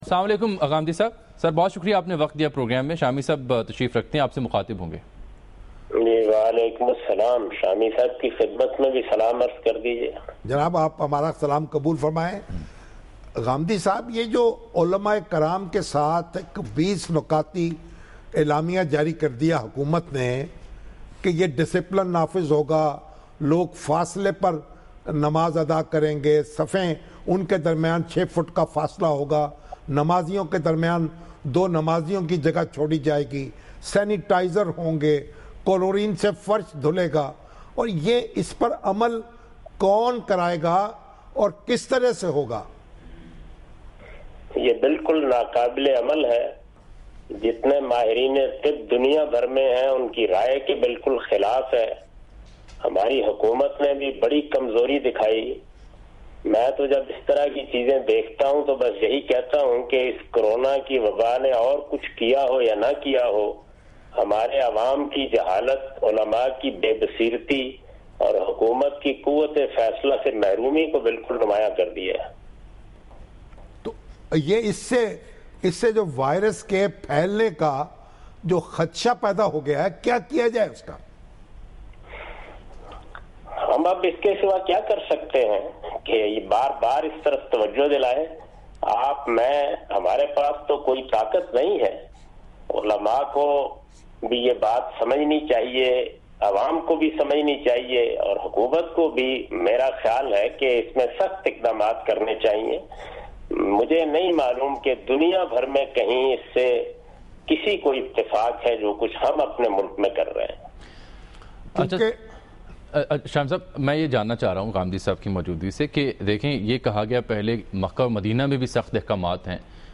Category: TV Programs / Dunya News / Questions_Answers /
دنیا نیوز کے اس پروگرام میں جناب جاوید احمد صاحب غامدی کورونا وائرس سے متعلق کچھ اہم سوالات کا جواب دے رہے ہیں۔